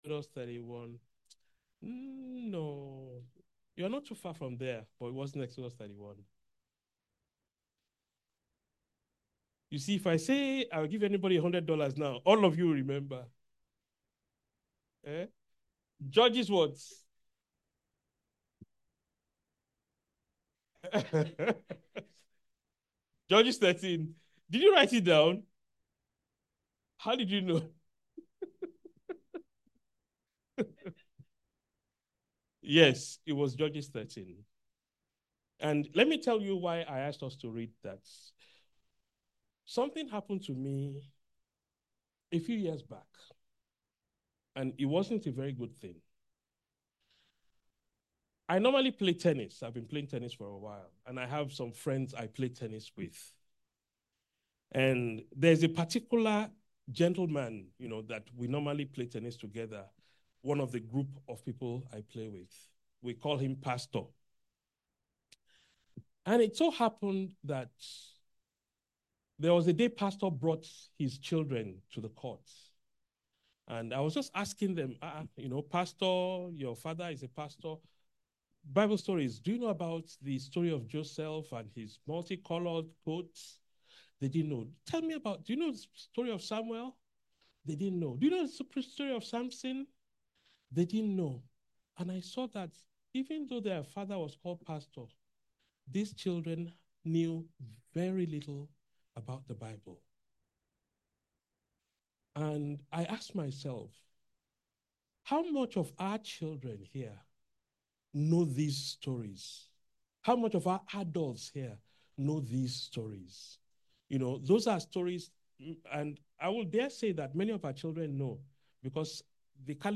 A message from the series "Sermons."